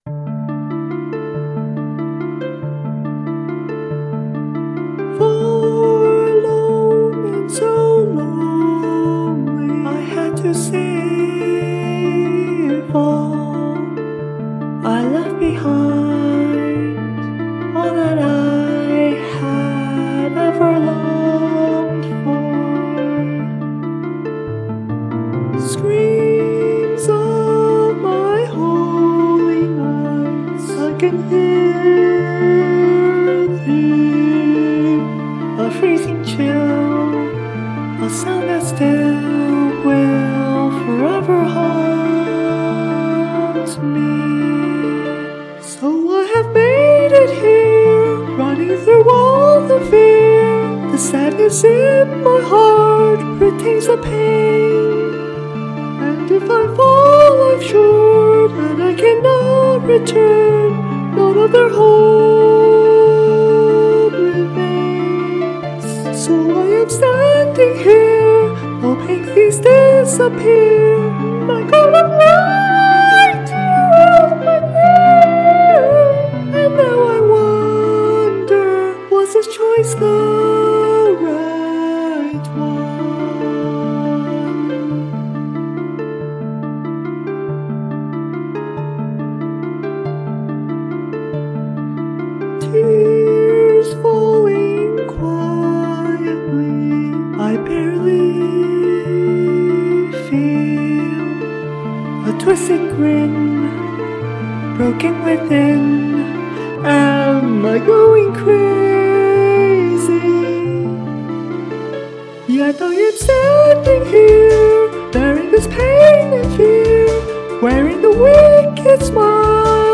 We finally finished singing covers for those songs!
Staff Rendition 1: